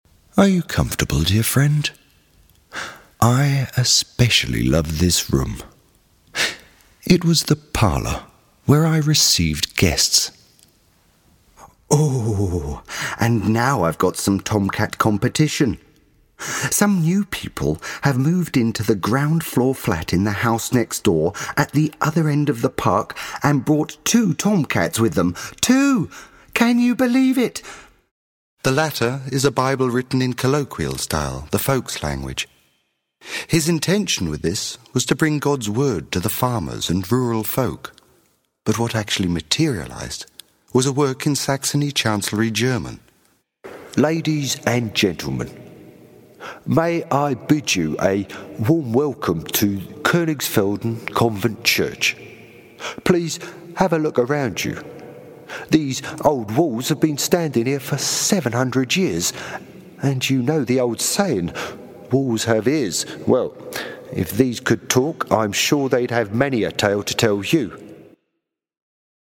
Seine tiefe, angenehme, sonore Stimme, die auch mal gern in Rollen schlüpft kennt man aus Dokumentarfilmen, Imagefilmen, Audioguides und aus seiner eigenen Radioshow!